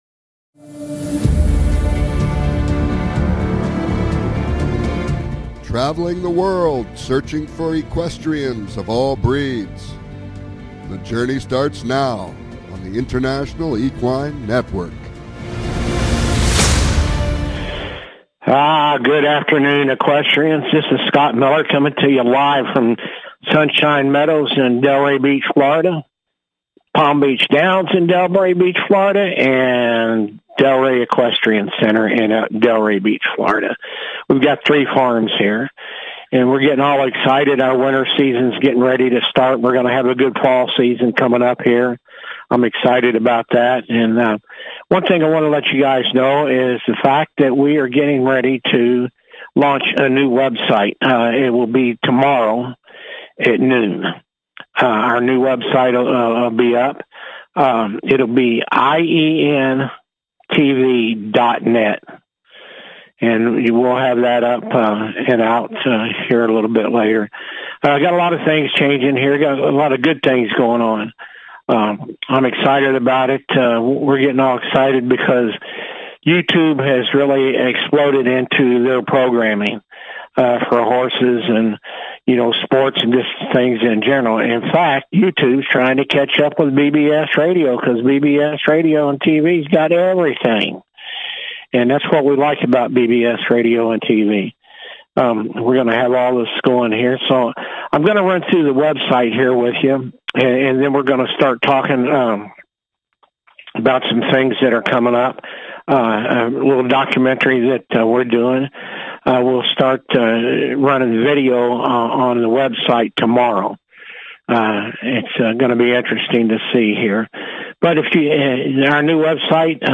Talk Show
Calls-ins are encouraged!